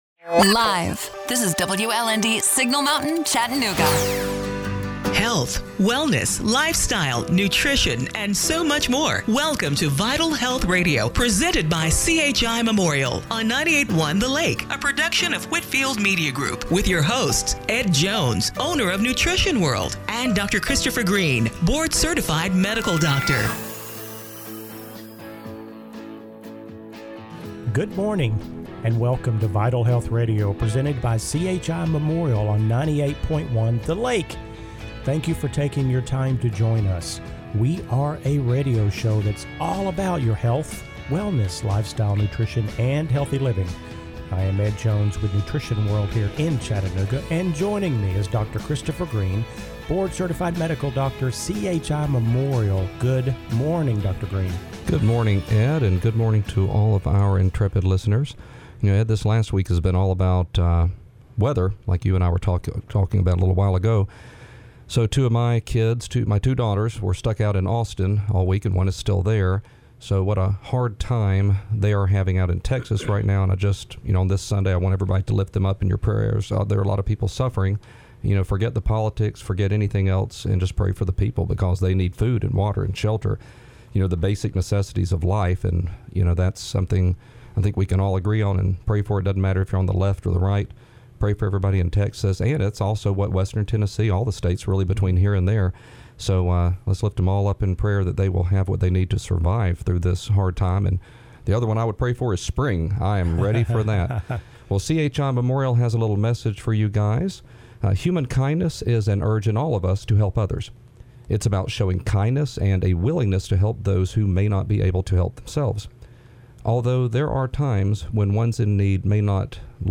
February 21, 2021 – Radio Show - Vital Health Radio